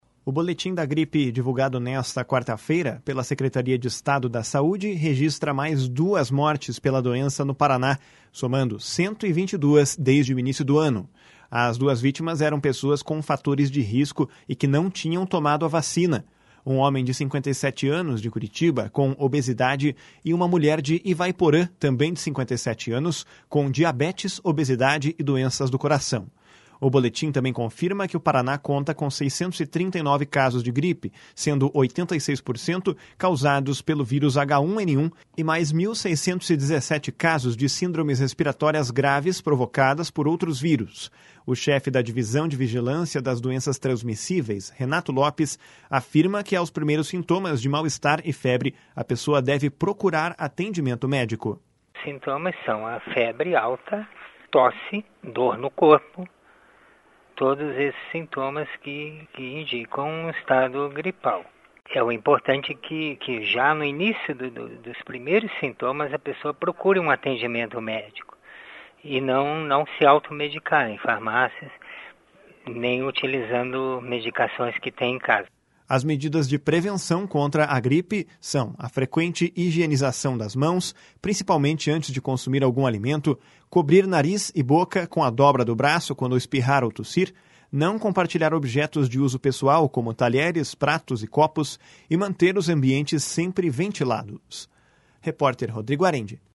As medidas de prevenção contra a gripe são a frequente higienização das mãos, principalmente antes de consumir algum alimento; cobrir nariz e boca com a dobra do braço quando espirrar ou tossir; não compartilhar objetos de uso pessoal como talheres, pratos e copos; e manter os ambientes sempre ventilados. (Repórter: